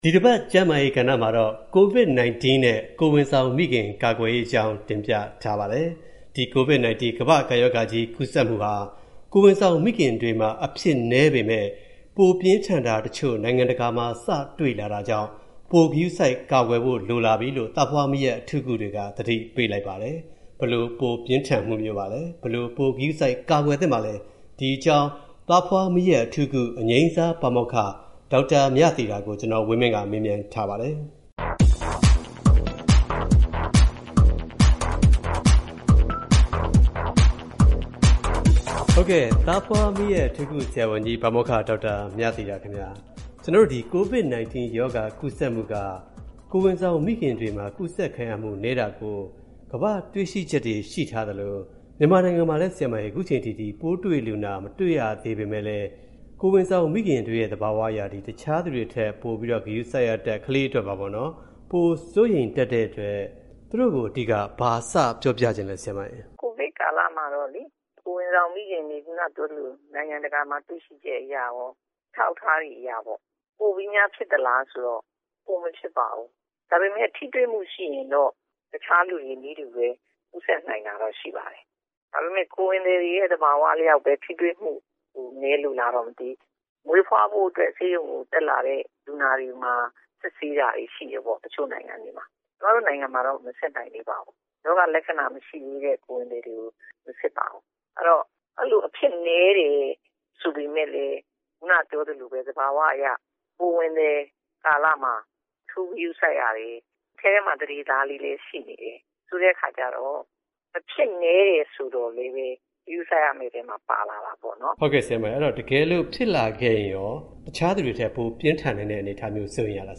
မေးမြန်းတင်ပြထားပါတယ်။